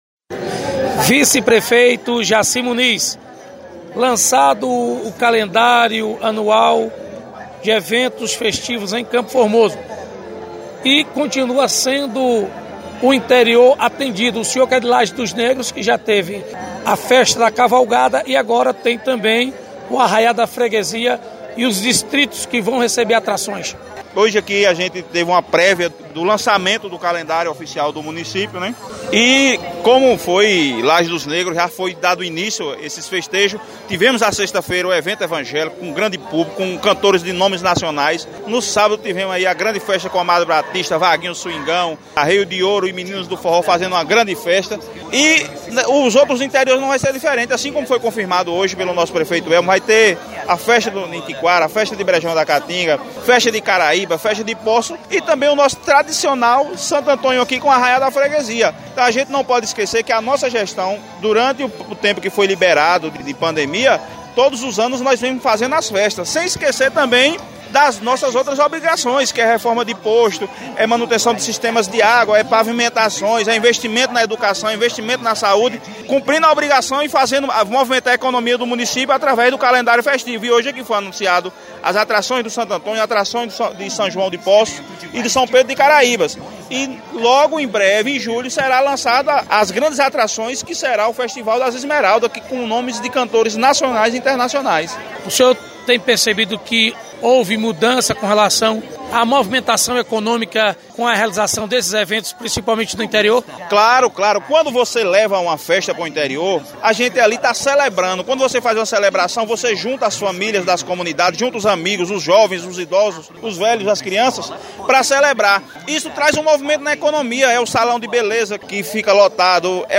Vice prefeito Jaci Muniz – fala sobre o lançamento do calendário dos festejos juninos no município de CF